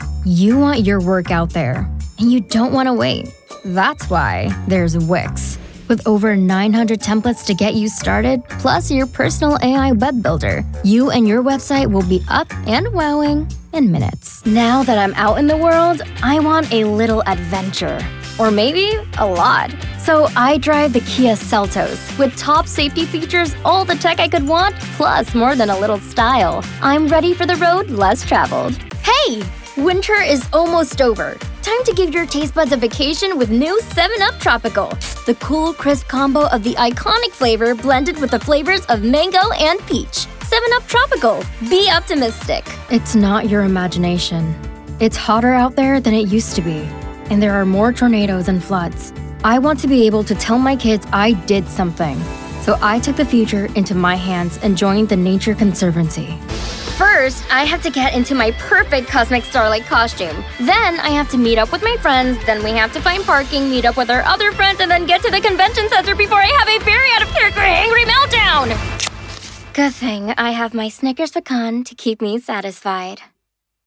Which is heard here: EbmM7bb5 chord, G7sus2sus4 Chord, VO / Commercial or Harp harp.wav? VO / Commercial